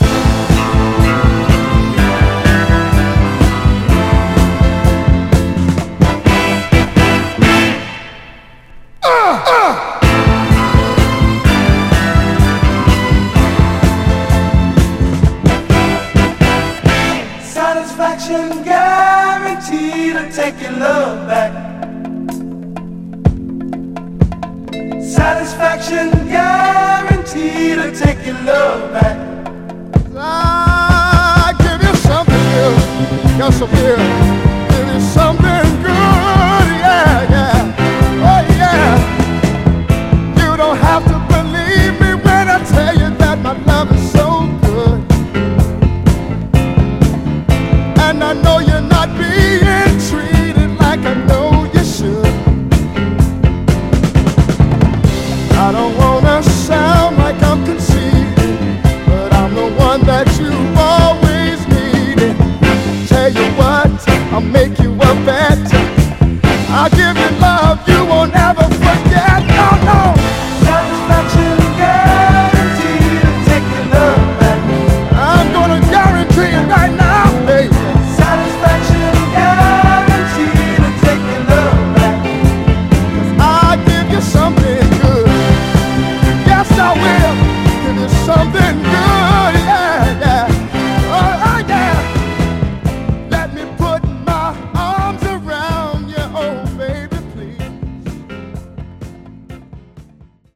タフなヴァイナル・プレス。
盤はエッジ中心にごく細かいスレ、いくつか薄い線スレ箇所ありますが、グロスがありプレイ良好です。
※試聴音源は実際にお送りする商品から録音したものです※